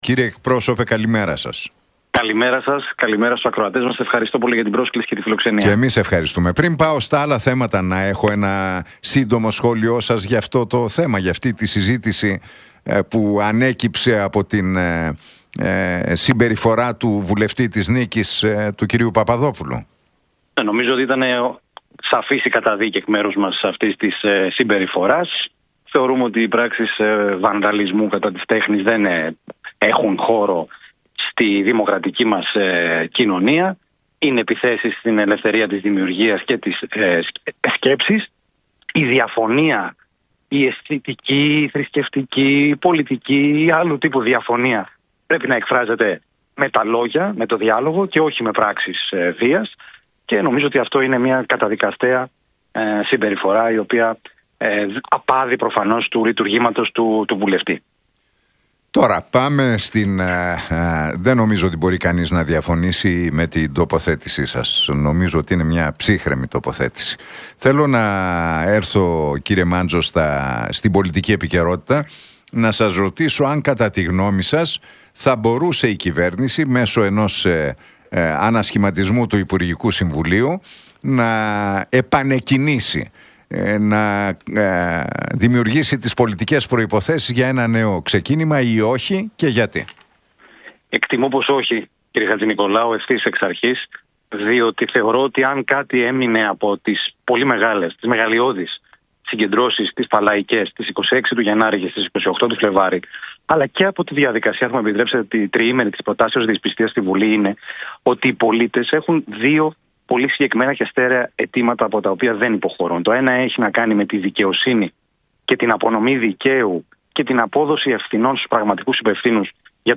Για τον επικείμενο ανασχηματισμό, την υπόθεση των Τεμπών, τις δημοσκοπήσεις αλλά και τις συνεργασίες με κόμματα της προοδευτικής παράταξης μίλησε ο κοινοβουλευτικός εκπρόσωπος του ΠΑΣΟΚ-ΚΙΝΑΛ, Δημήτρης Μάντζος στον Νίκο Χατζηνικολάου από την συχνότητα του Realfm 97,8.